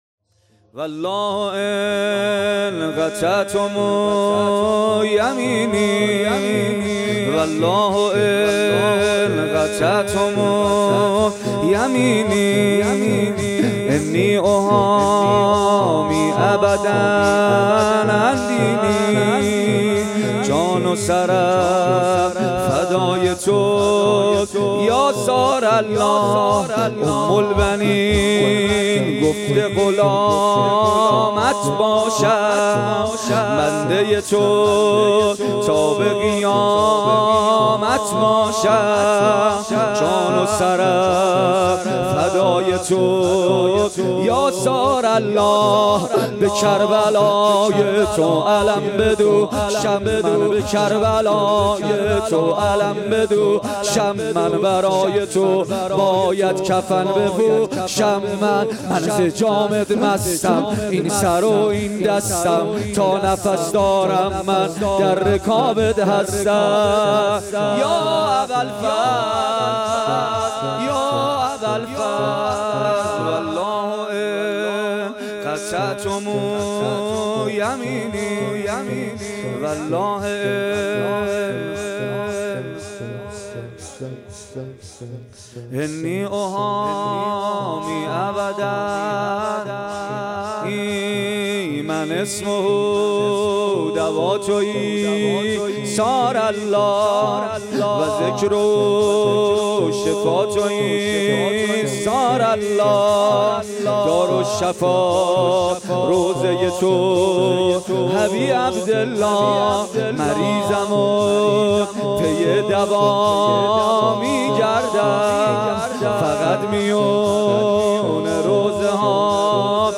مداحی
محرم 1399 هیئت ریحانه النبی تهران